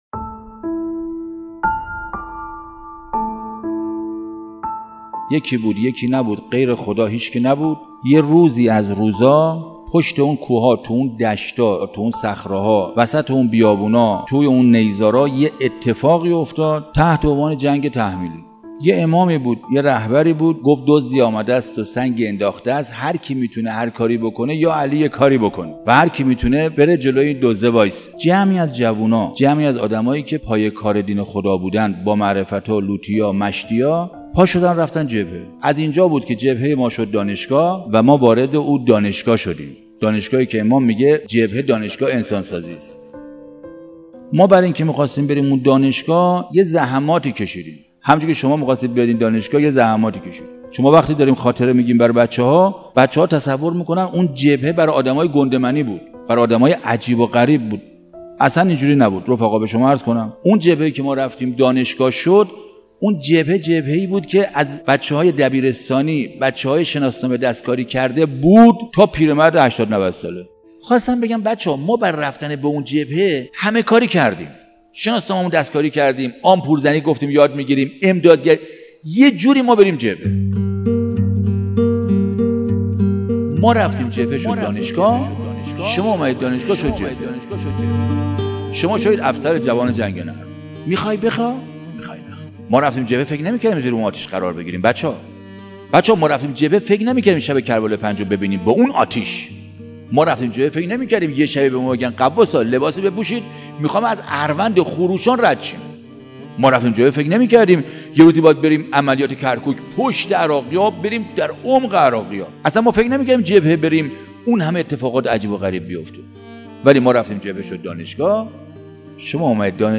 حاج حسین یکتا راوی خوش بیان دفاع مقدس، قصه ی خوش تیپ از جبهه و دانشگاه تا پیاده روی اربعین را روایت می کند...